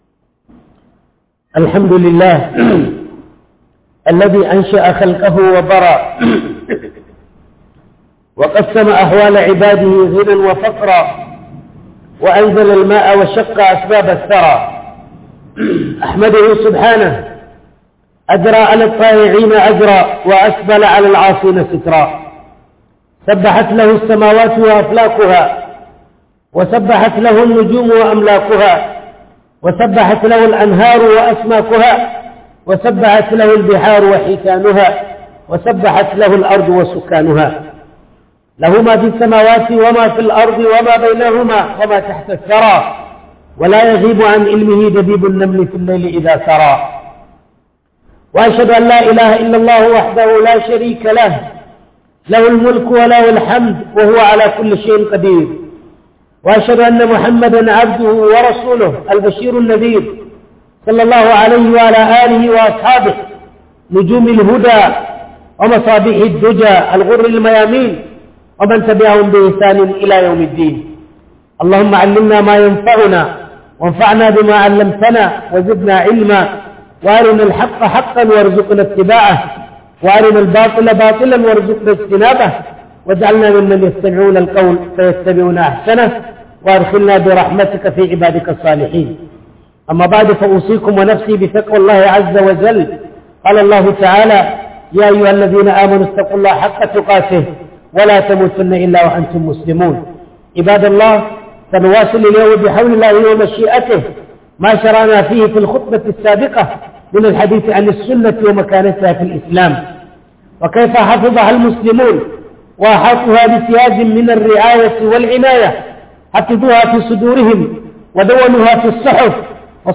Darussa daga tarihin sunnah - Huduba